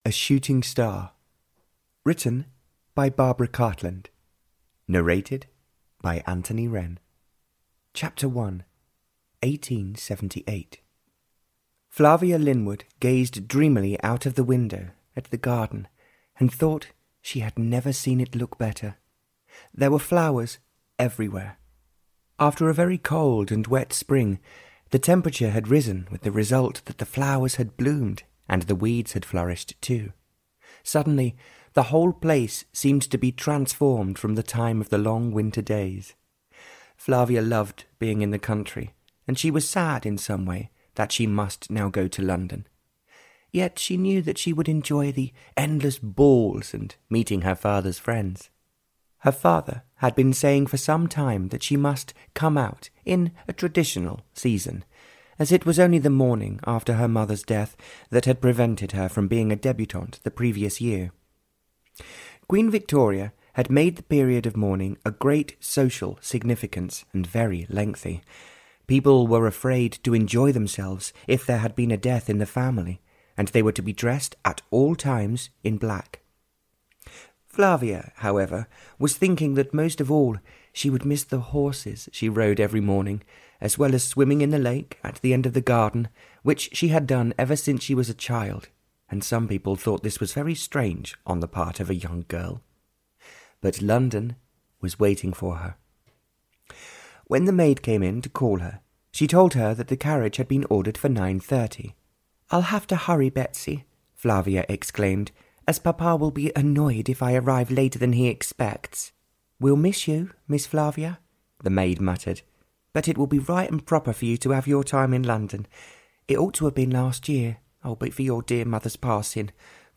Audio knihaA Shooting Star (Barbara Cartland s Pink Collection 90) (EN)
Ukázka z knihy